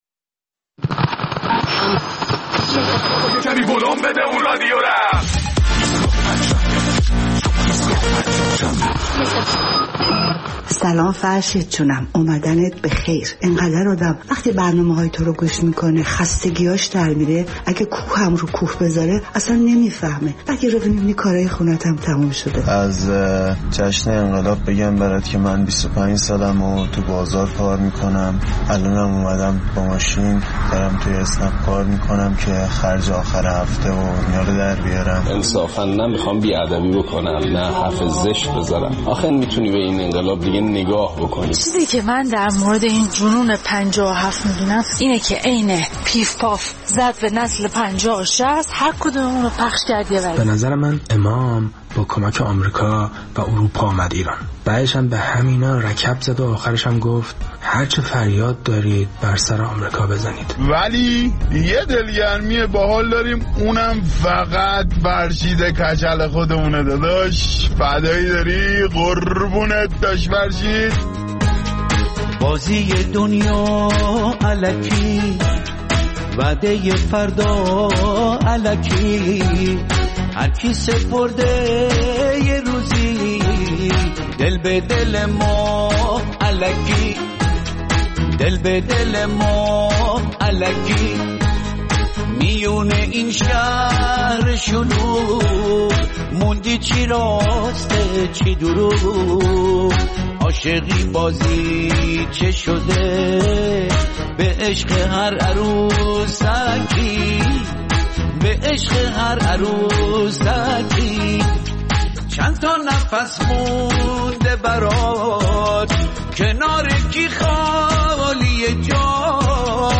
در این ایستگاه فردا ادامه نظرات مخاطبین برنامه را درباره ۴۰ سالگی انقلاب و روایت‌شان را از تاثیراتی که این انقلاب بر زندگی خود و اطرافیانشان گذاشته می‌شنویم.